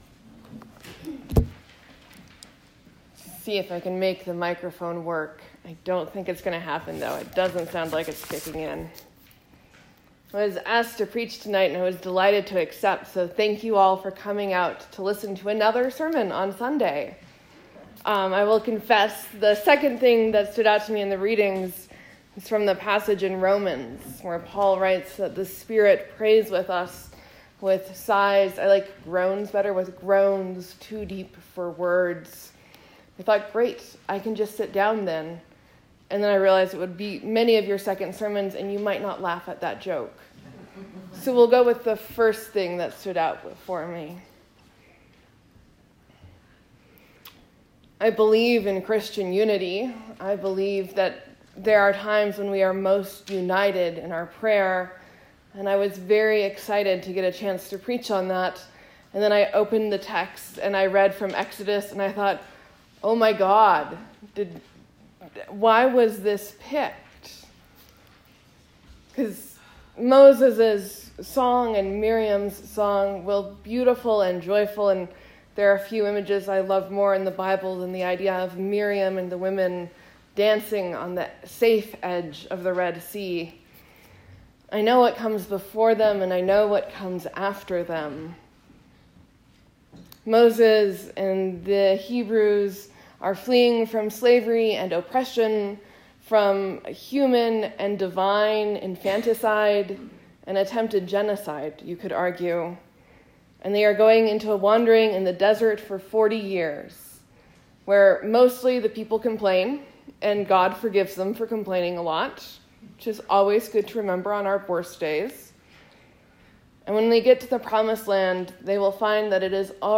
Sermon, Uncategorized, , , , Leave a comment